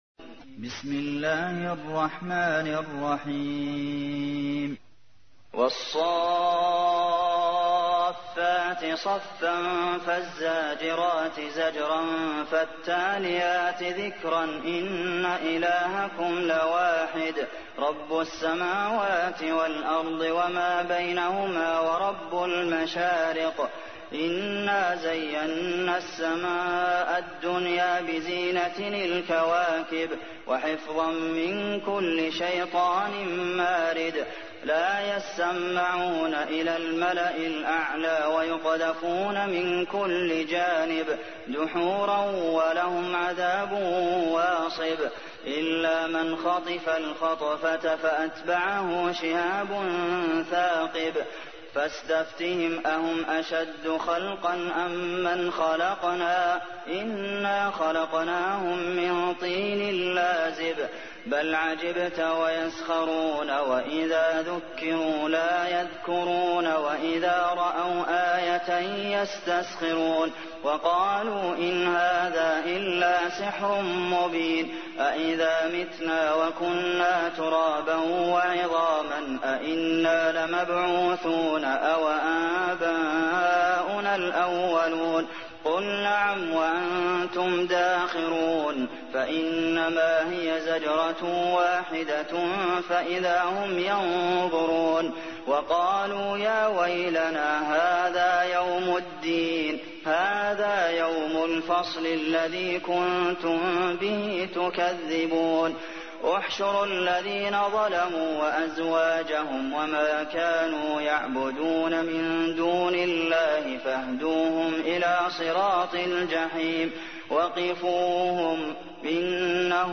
تحميل : 37. سورة الصافات / القارئ عبد المحسن قاسم / القرآن الكريم / موقع يا حسين